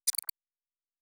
pgs/Assets/Audio/Sci-Fi Sounds/Interface/Error 13.wav at master
Error 13.wav